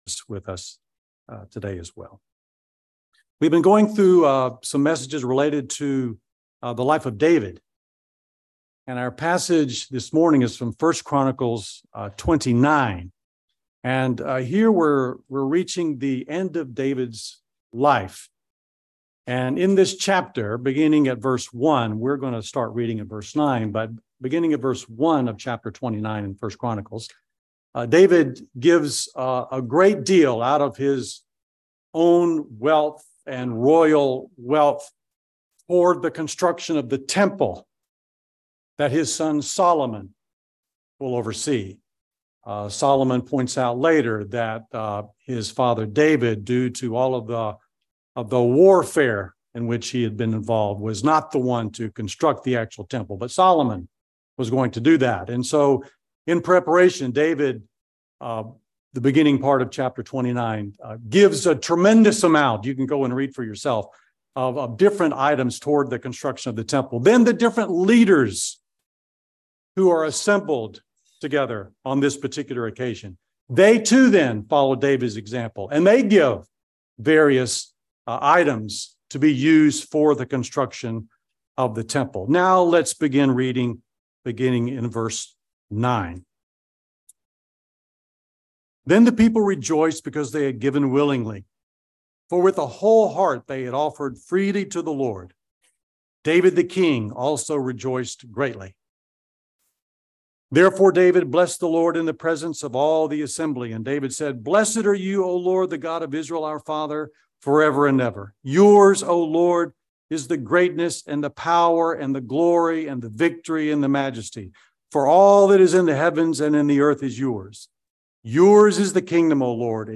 Sermon Text: I Chronicles 29:9-16